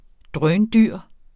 Udtale [ ˈdʁœːnˈdyɐ̯ˀ ]